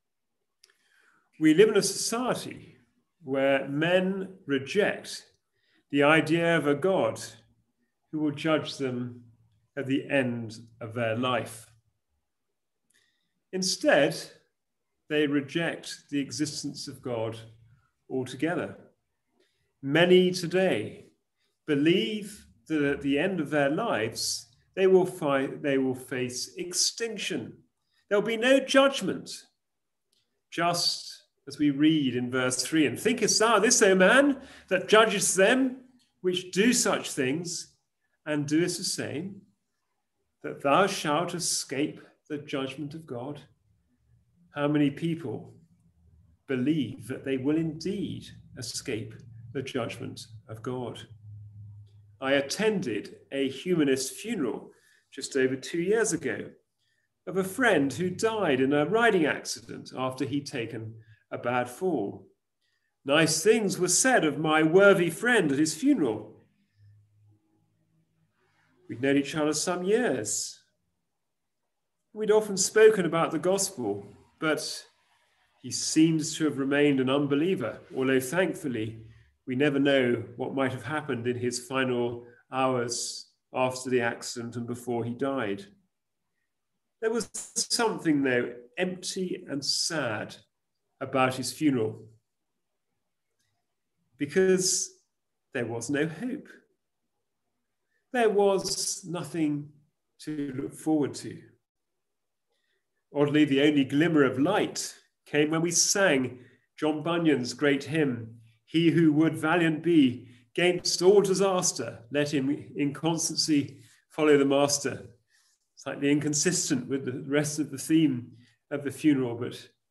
Romans 2:1-11 Service Type: Sunday Evening Service « “